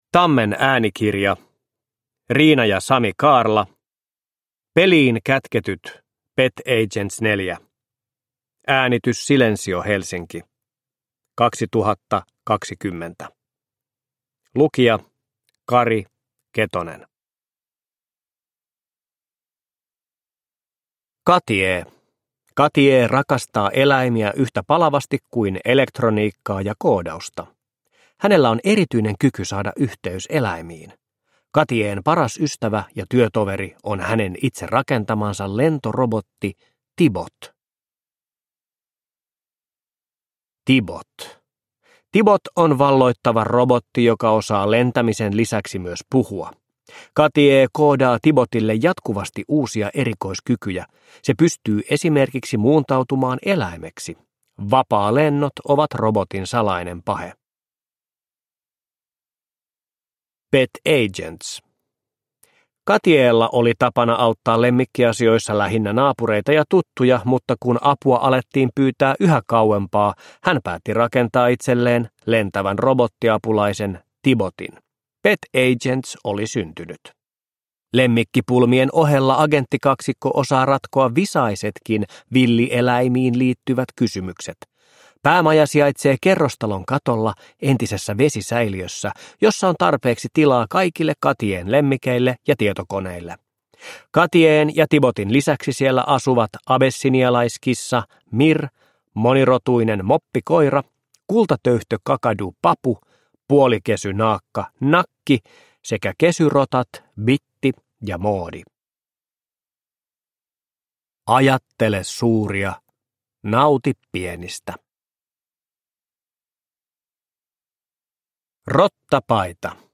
Produkttyp: Digitala böcker
Uppläsare: Kari Ketonen